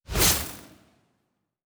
Ice Spelll 22.wav